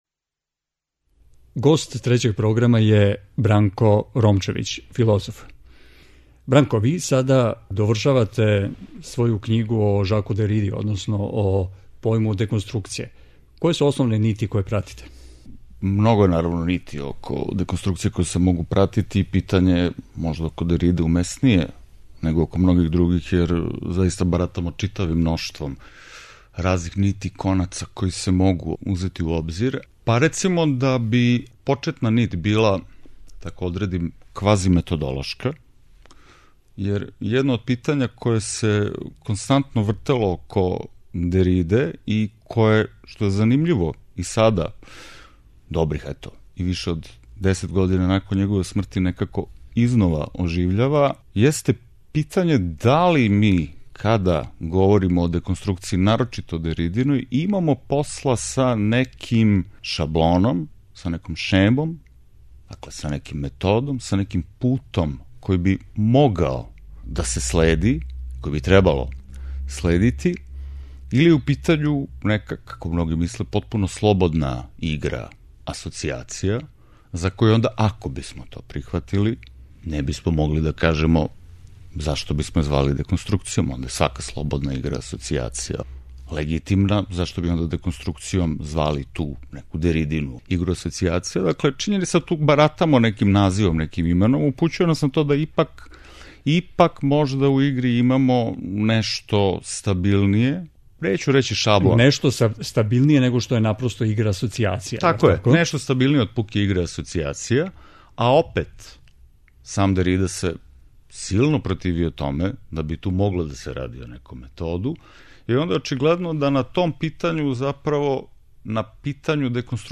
Разговор о савременој филозофији